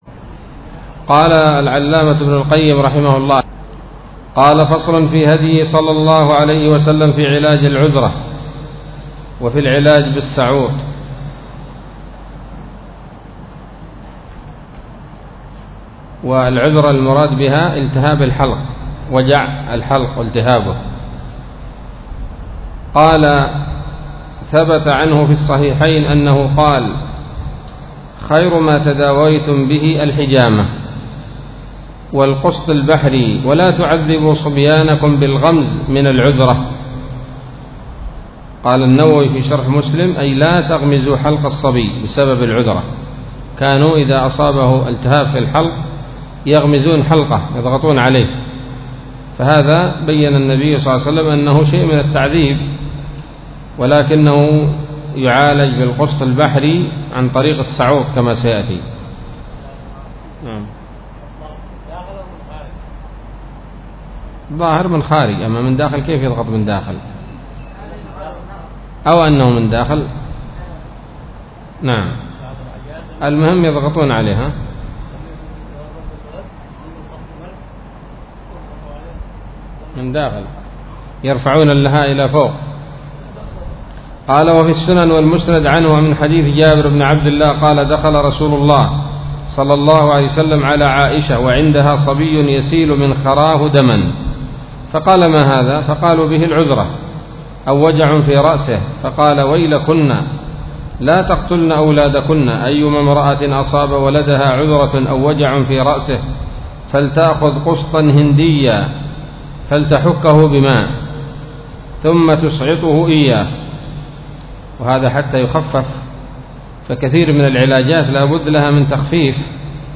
الدرس الخامس والعشرون من كتاب الطب النبوي لابن القيم